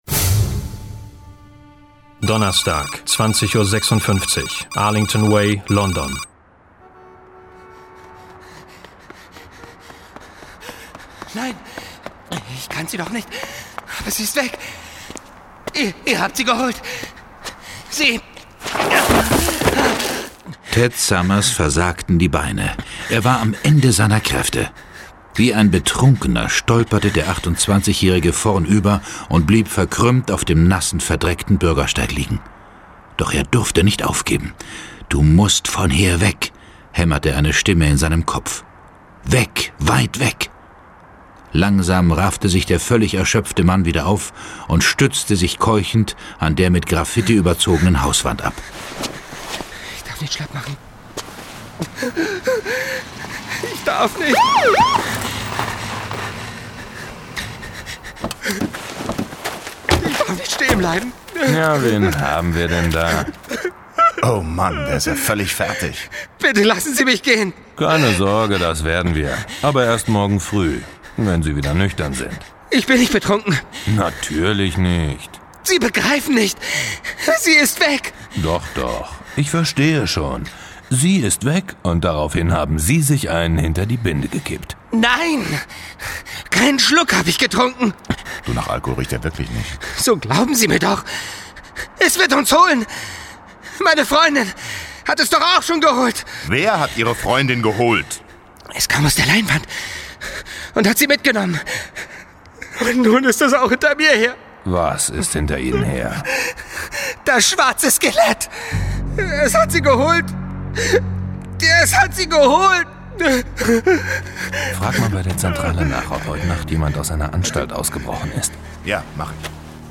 John Sinclair - Folge 11 Kino des Schreckens. Hörspiel.